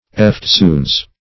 Eftsoon \Eft*soon"\, Eftsoons \Eft*soons"\, adv.